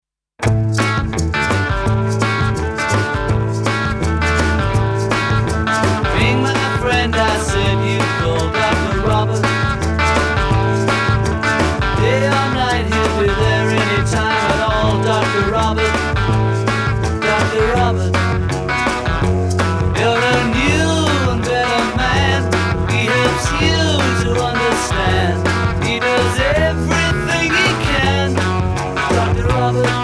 sólová kytara
bicí